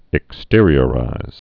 (ĭk-stîrē-ə-rīz)